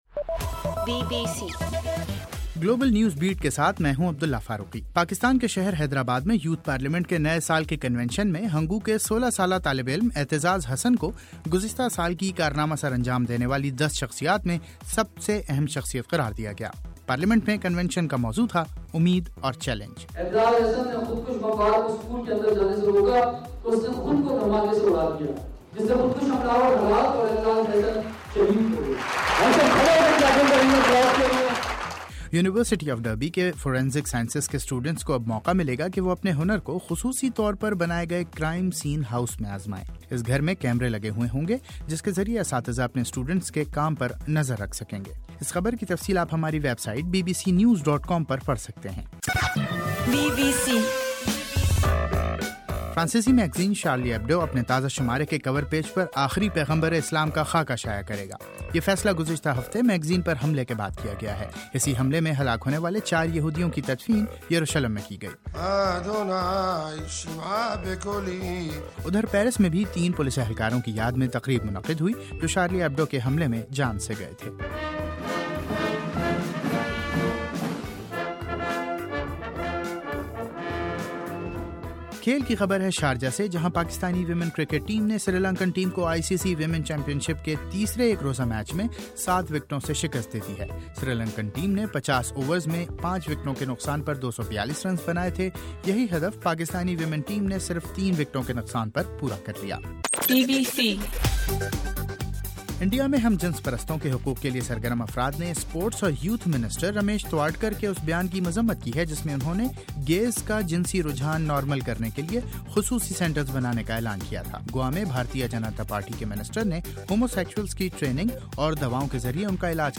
جنوری 13: رات 9 بجے کا گلوبل نیوز بیٹ بُلیٹن